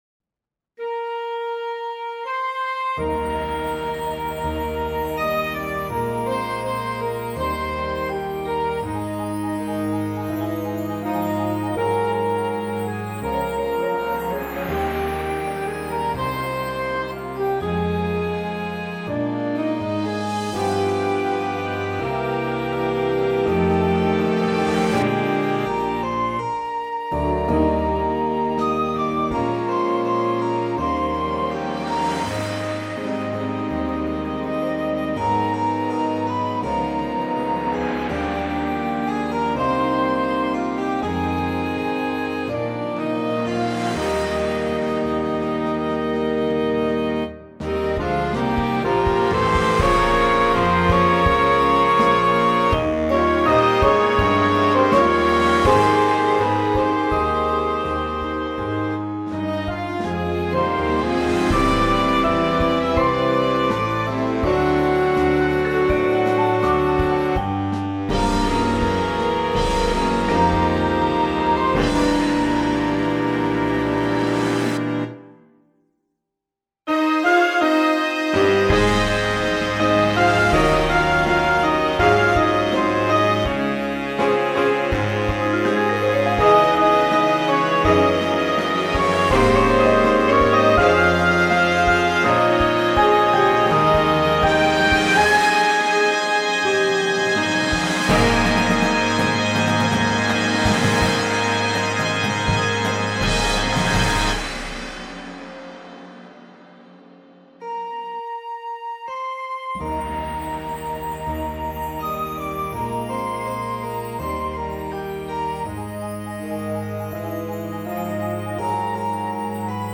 • Flute
• Trombone 1, 2
• Tuba
• Snare Drum
• Bass Drums